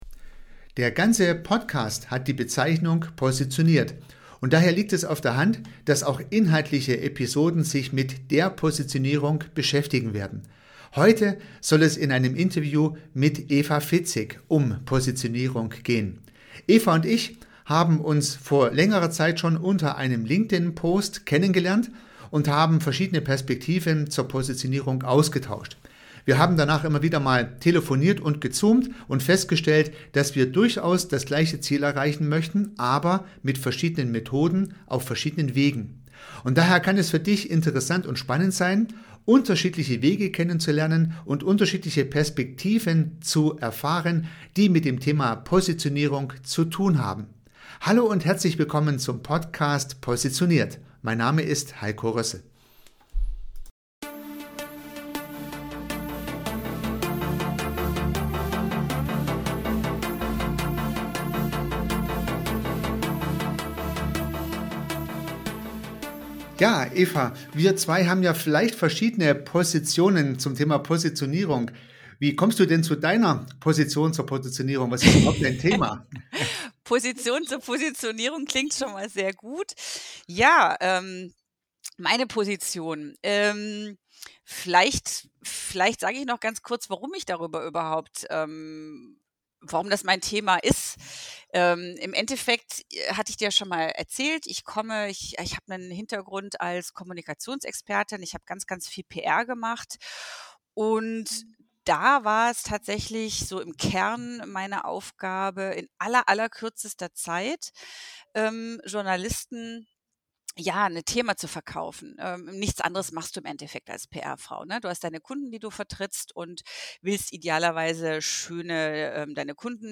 P002 POSITIONIERUNG (I) - so findest Du Deine passende Positionierung, das Interview